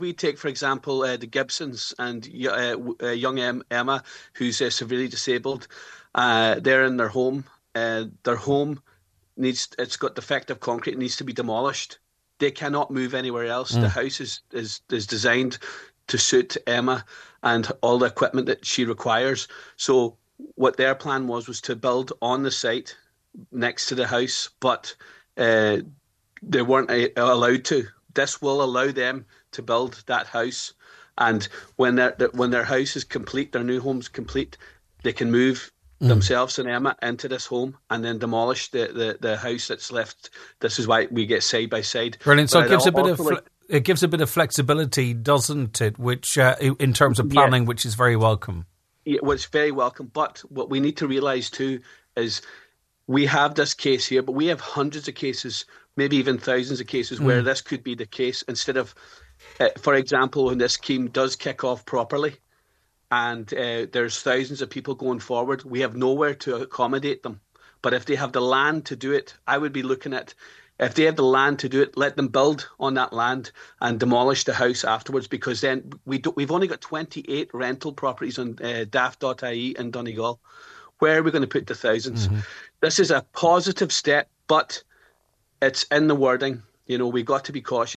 100% Redress Deputy Charles Ward says accommodation has been a chronic issue: